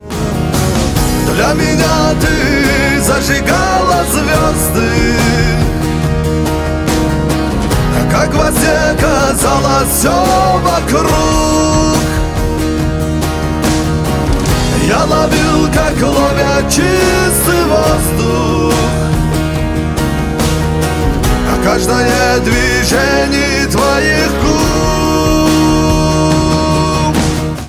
• Качество: 128, Stereo
гитара
мужской вокал
русский шансон
русский рок